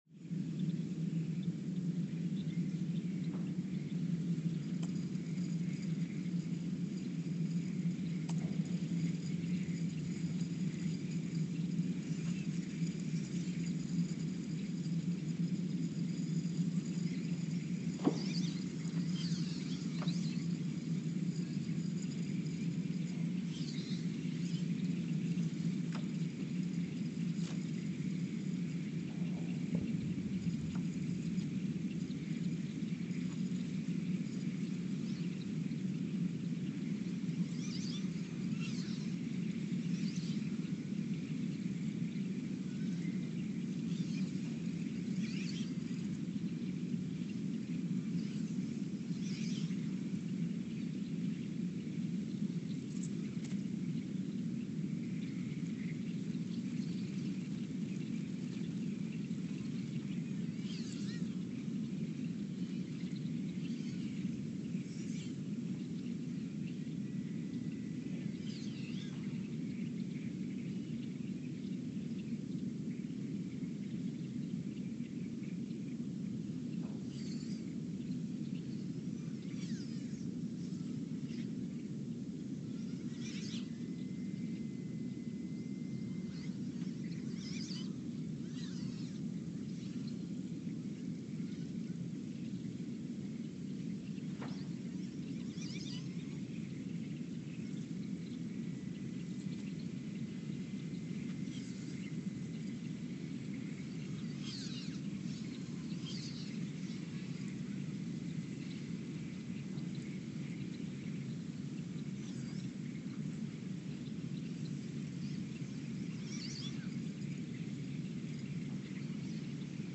The Earthsound Project is an ongoing audio and conceptual experiment to bring the deep seismic and atmospheric sounds of the planet into conscious awareness.
Sensor : STS-1V/VBB
Speedup : ×900 (transposed up about 10 octaves)
Loop duration (audio) : 11:12 (stereo)